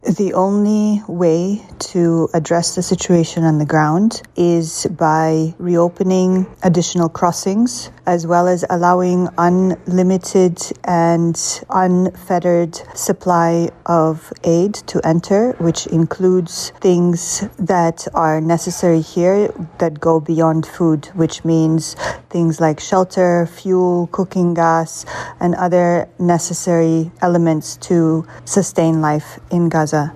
speaking from Khan Younis